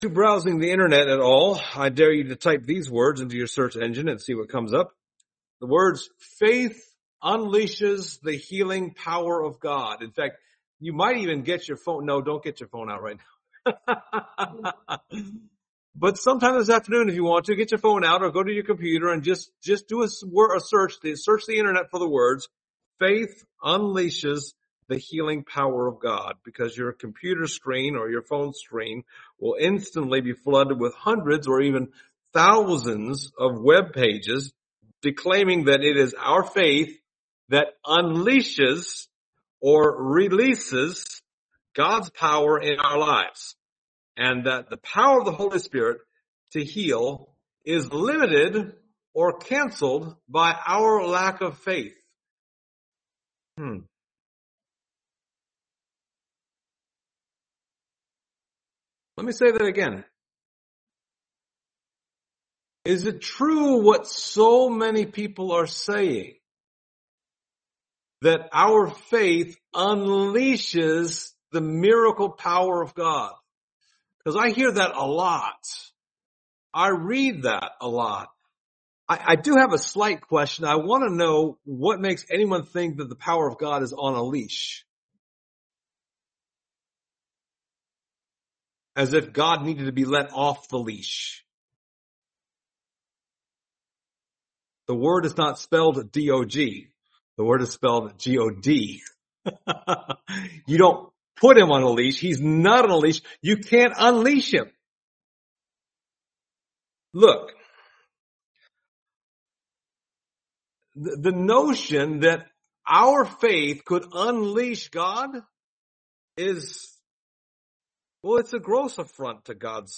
Service Type: Sunday Morning Topics: faith , healing , miracles